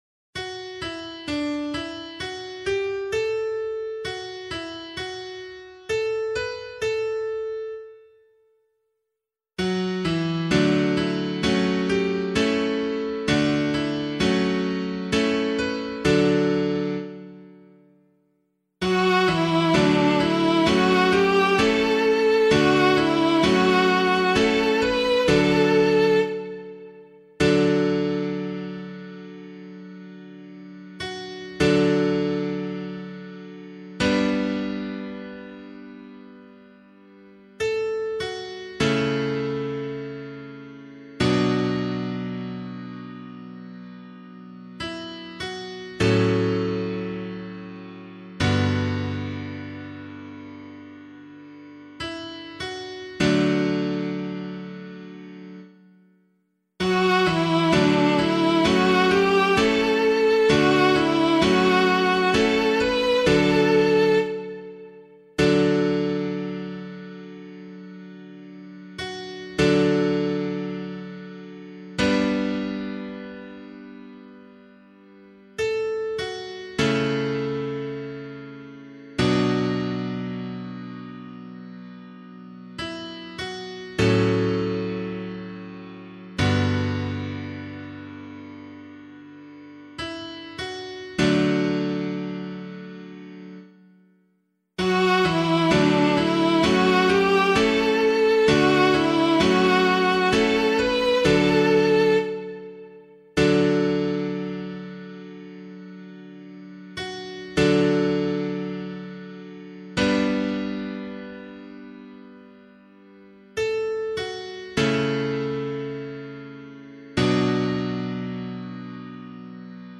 039 Ordinary Time 5 Psalm C [APC - LiturgyShare + Meinrad 5] - piano.mp3